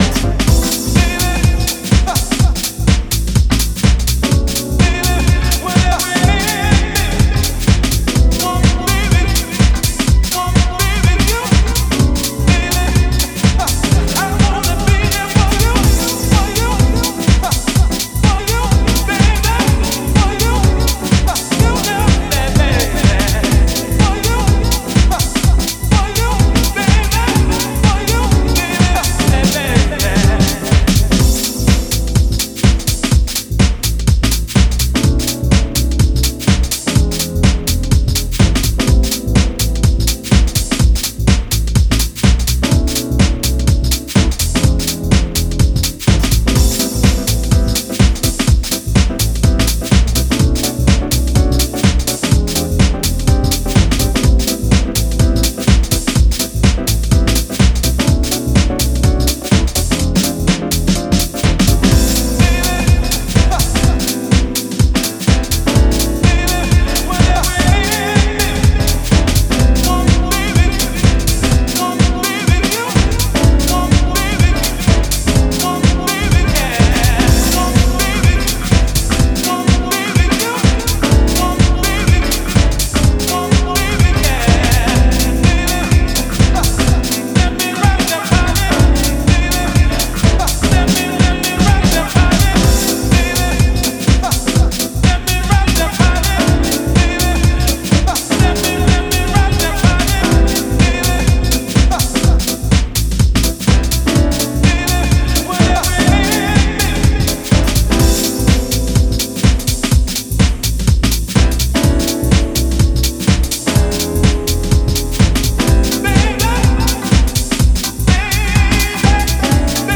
絢爛なピアノとソウルフルなヴォイス・サンプルがウォームでオーセンティックな魅力を放つディープ・ハウス